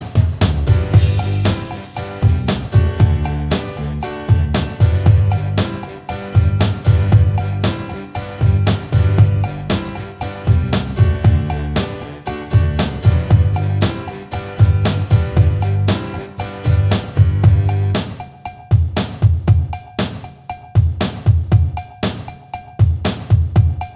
Minus Guitars Rock 3:03 Buy £1.50